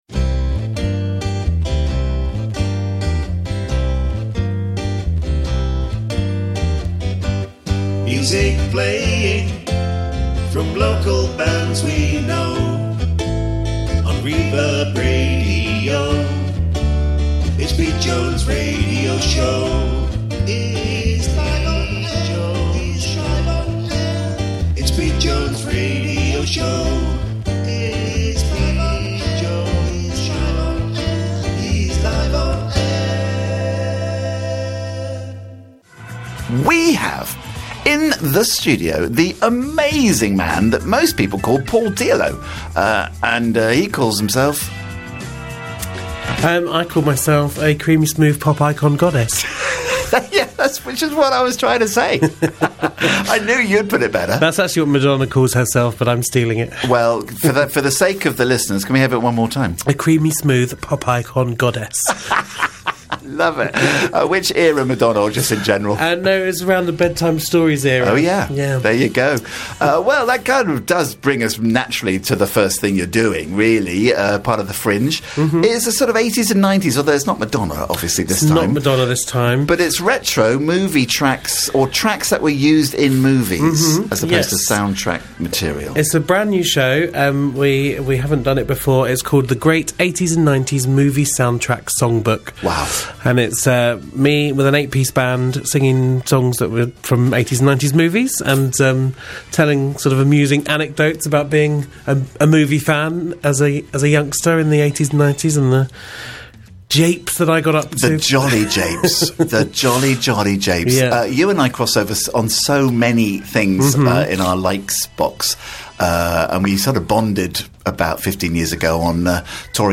Live chat